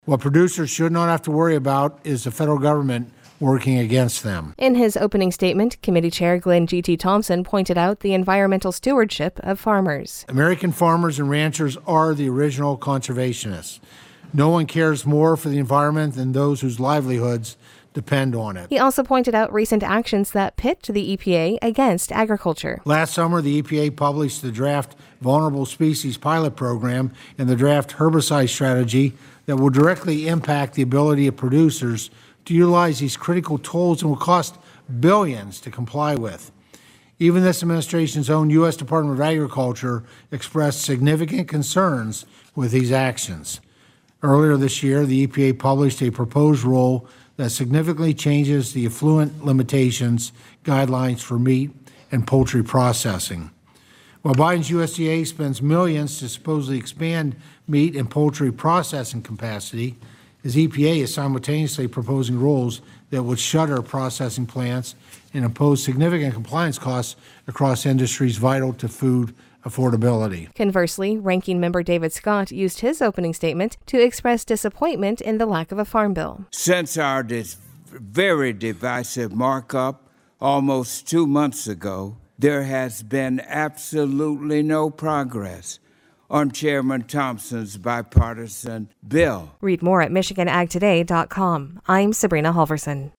Rep. Glenn “G.T.” Thompson (R-PA-15), who serves as Chair of the House Ag Committee, during a hearing held Wednesday over the consequences of EPA’s actions on U.S. agriculture.